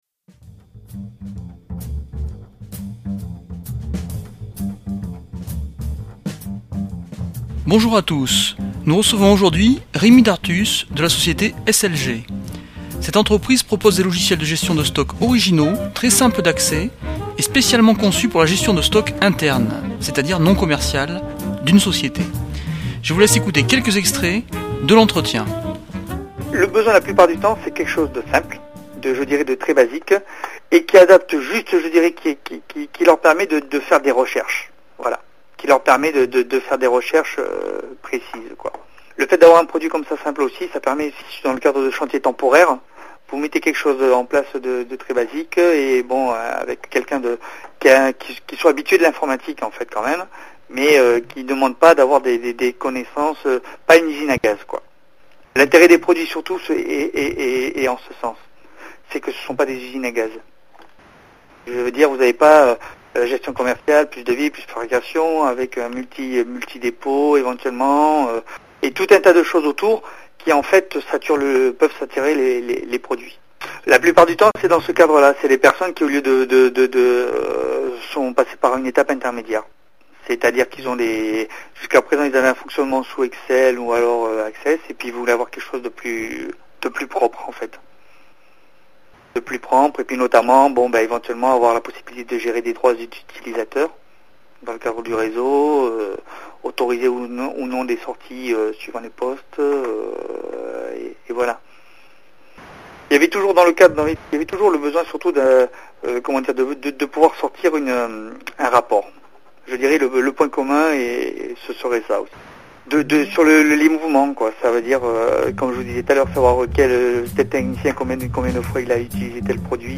interview logiciel de gestion de stock SLG
interview-slg.mp3